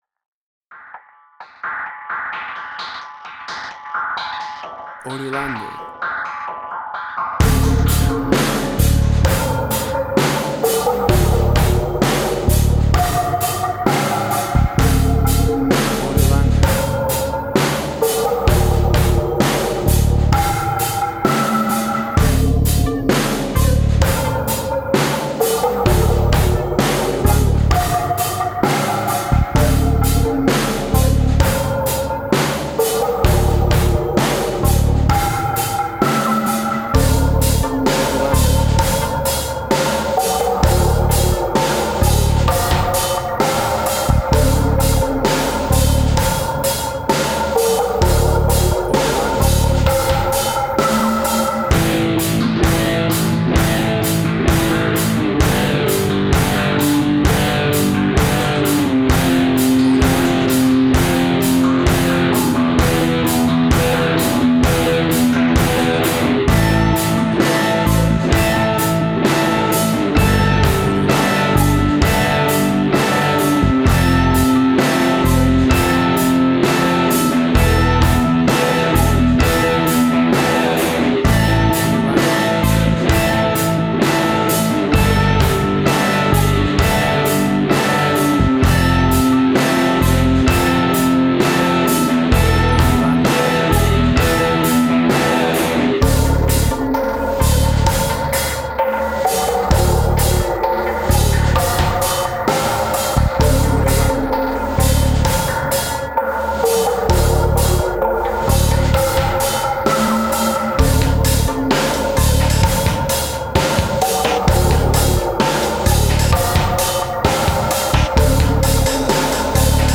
Trip Hop Similar Tricky-Massive Attack.
emotional music
Tempo (BPM): 65